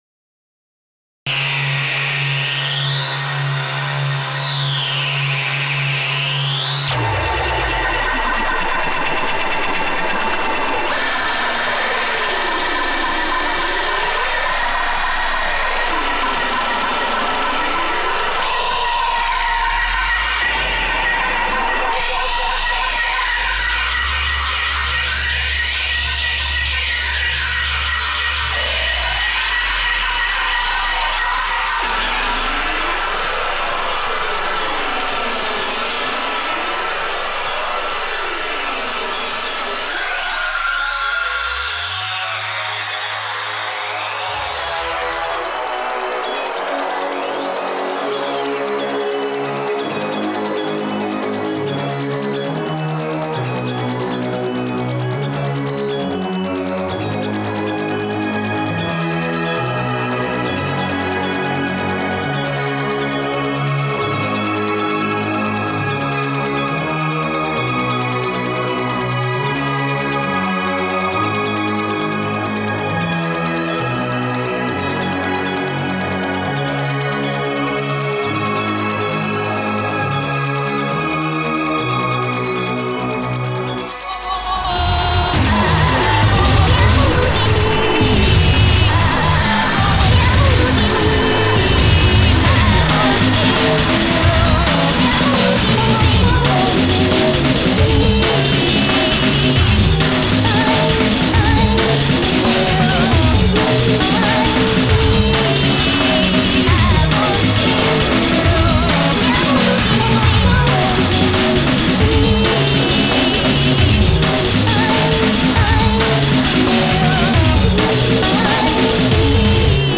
breakcore, glitch, electronica,